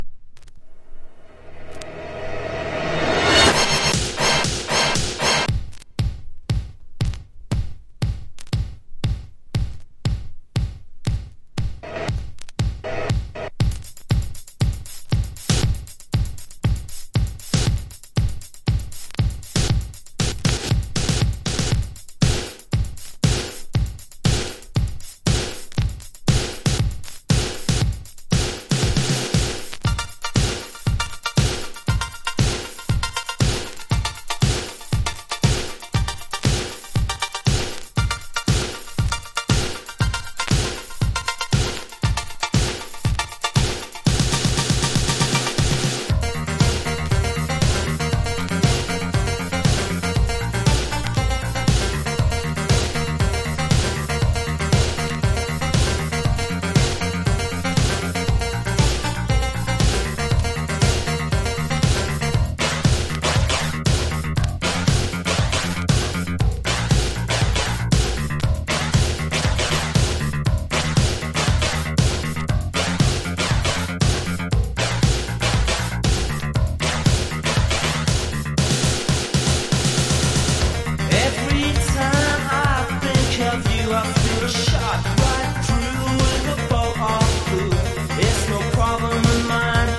POPS# NEWWAVE DISCO# ELECTRO POP